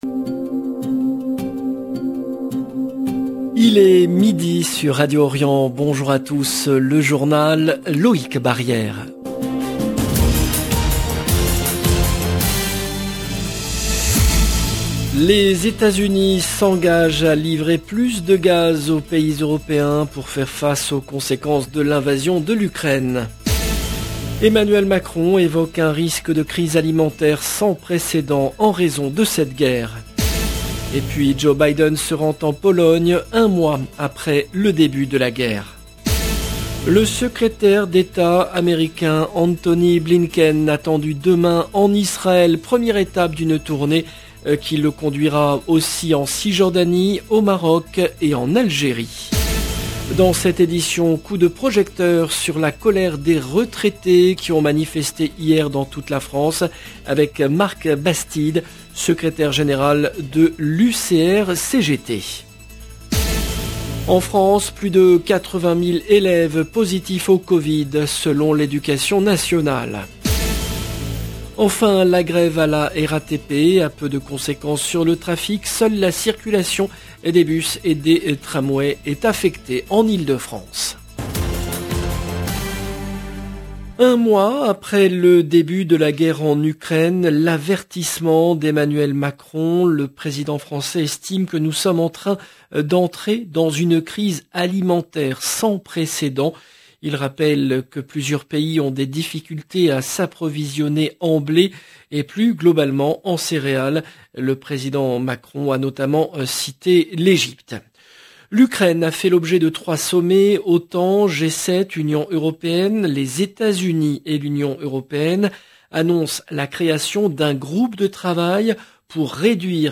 LE JOURNAL DE MIDI EN LANGUE FRANCAISE DU 25/03/22